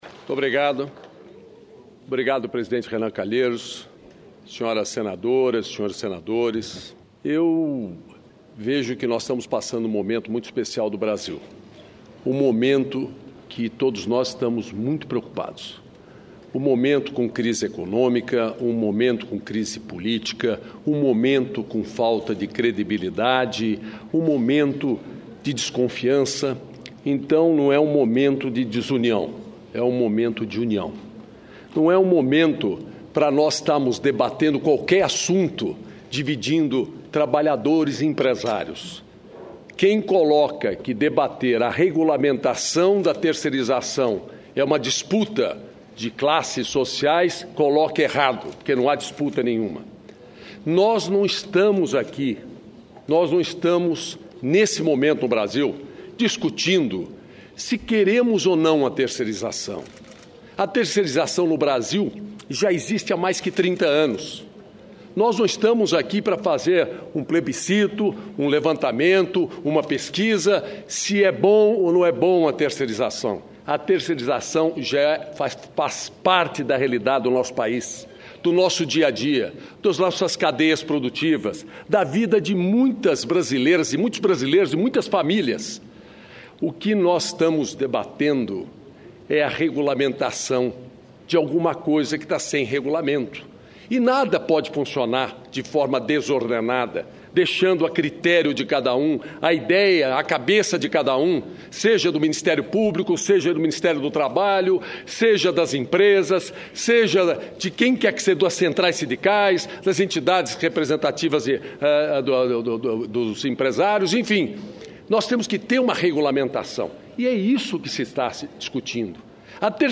Pronunciamento de Paulo Skaf, presidente da Fiesp